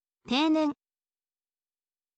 teinen